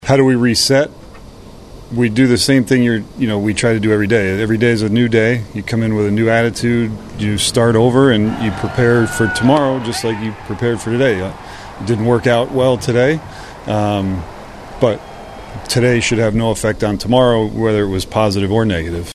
Manager Matt Quatraro was asked after the game, “How do you reset?”